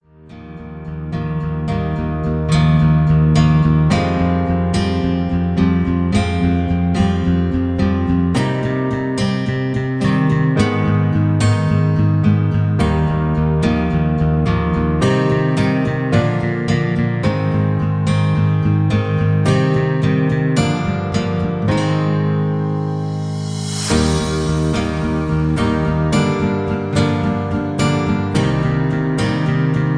Karaoke - 320kbps